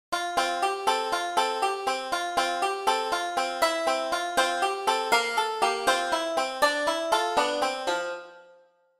Banjo_-_SOUND_EFFECT_-.mp3